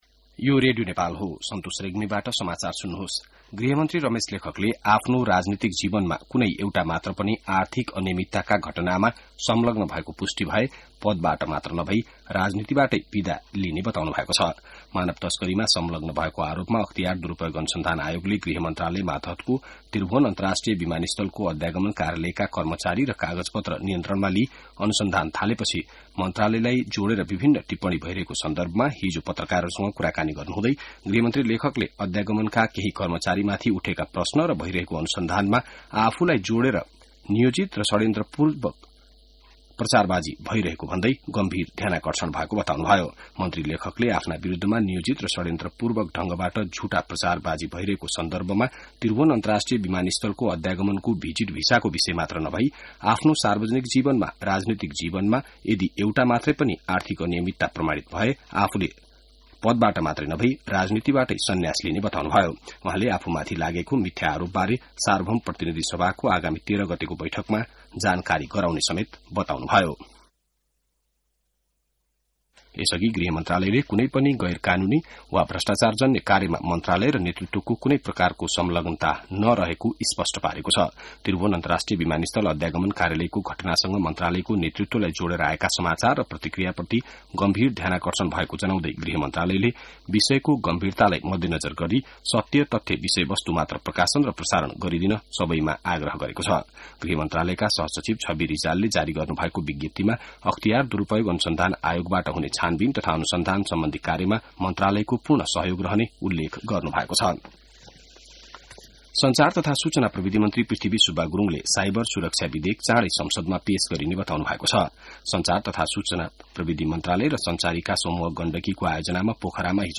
बिहान ६ बजेको नेपाली समाचार : ११ जेठ , २०८२